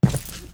FootstepHeavy_Concrete 01.wav